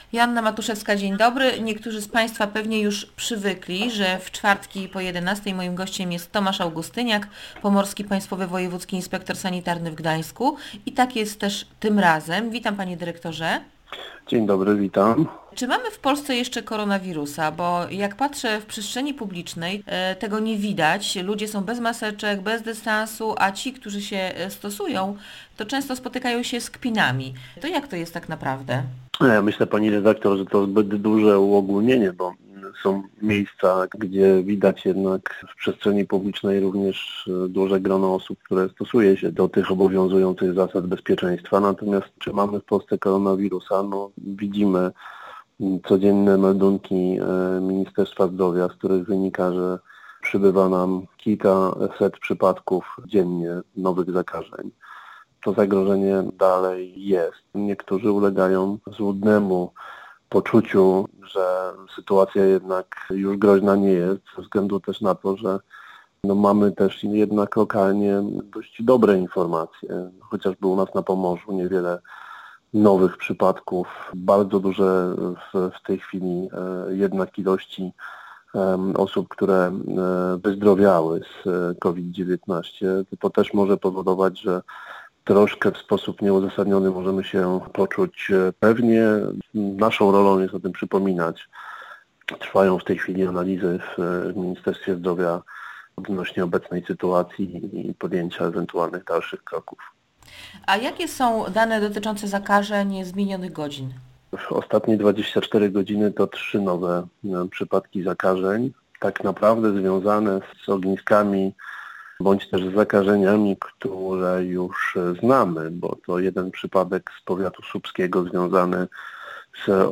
Na Pomorzu są 72 aktywne przypadki zachorowań na covid19 oraz trzy aktywne ogniska wirusa SARS-Cov2 - mówił w Radiu Gdańsk